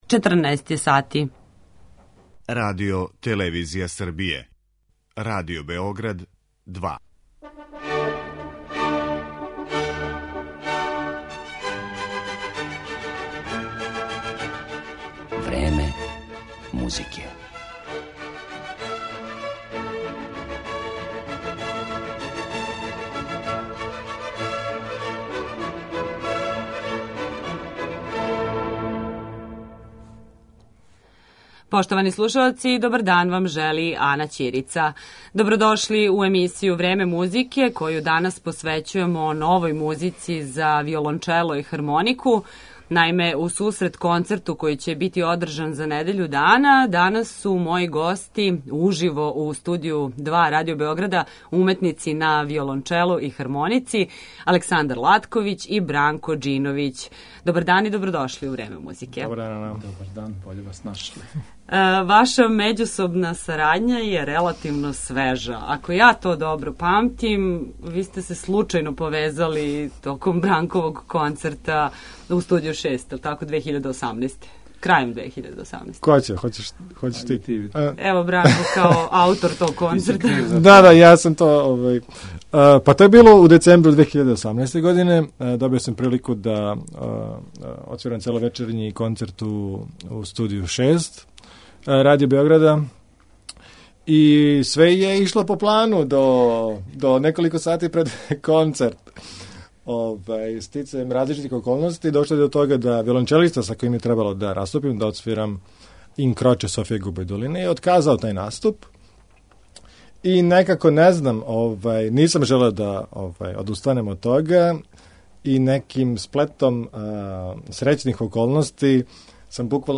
Разговарамо са интерпретаторима нове музике за хармонику и виолончело.